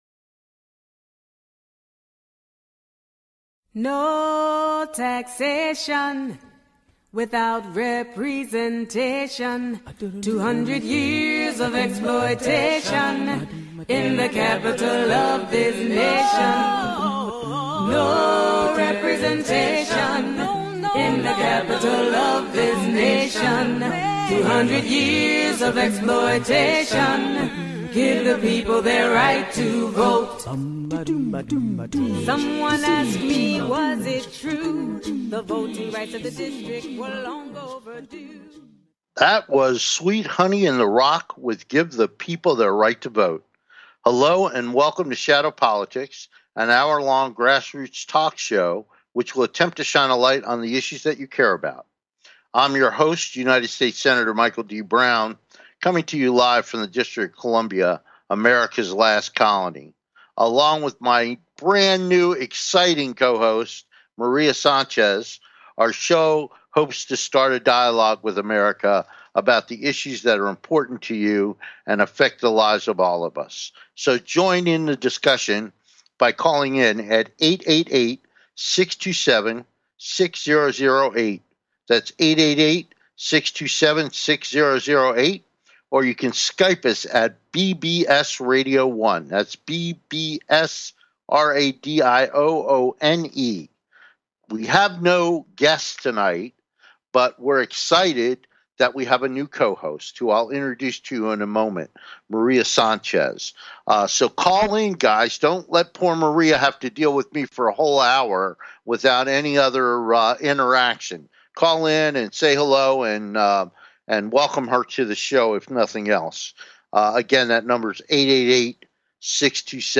Shadow Politics is a grass roots talk show giving a voice to the voiceless. For more than 200 years the people of the Nation's Capital have ironically been excluded from the national political conversation.
We look forward to having you be part of the discussion so call in and join the conversation.